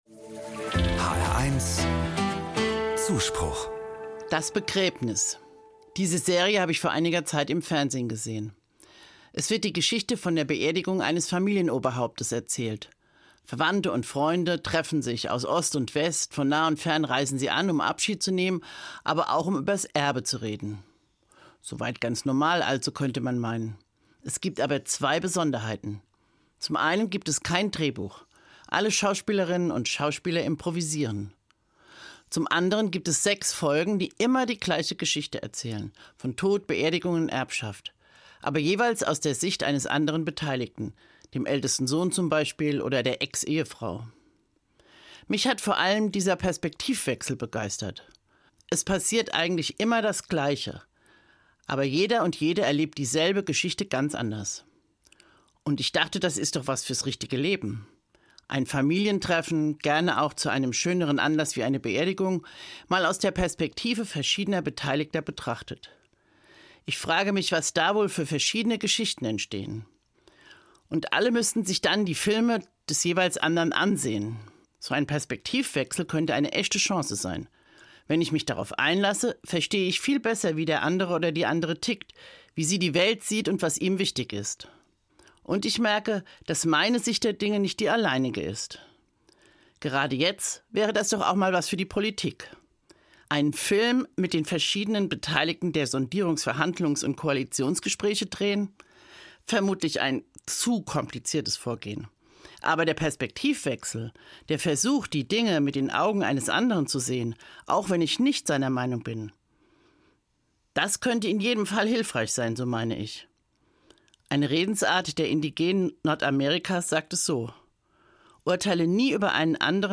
katholische Pastoralreferentin im Ruhestand, Frankfurt